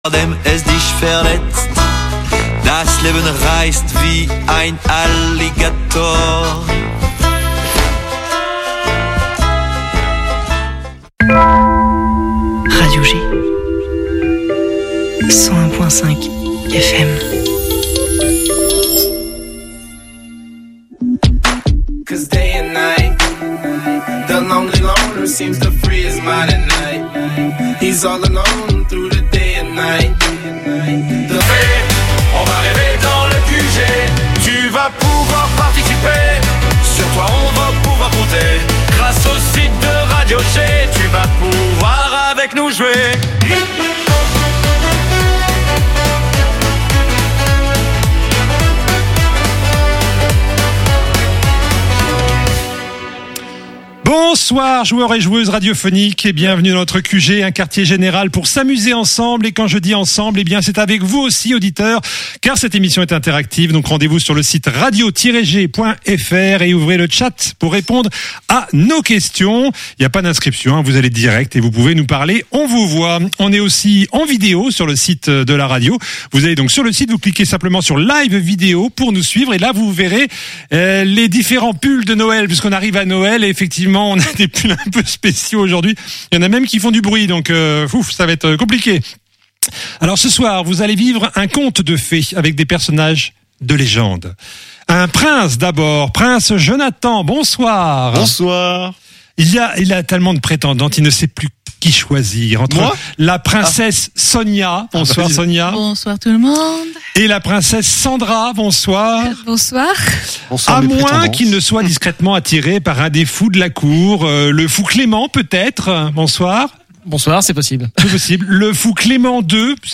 Le QG, le programme radio de jeux de Radio G!